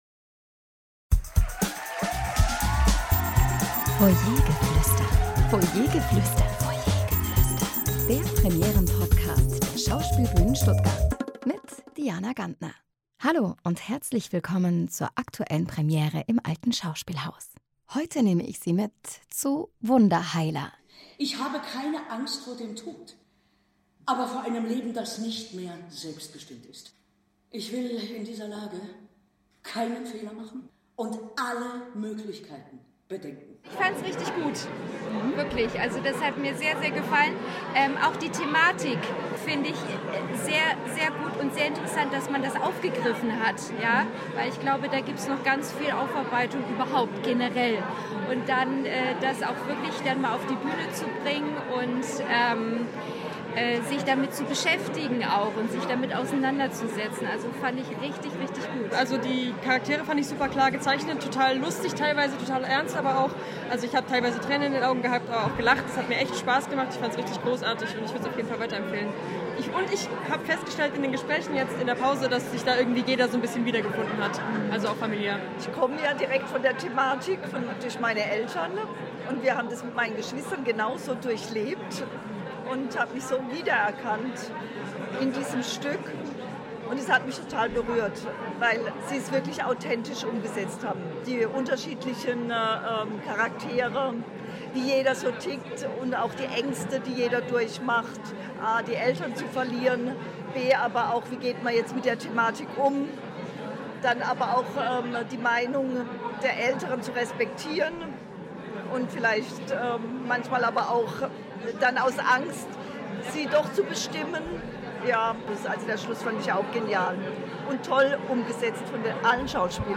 Publikumsstimmen zur Premiere von “Wunderheiler”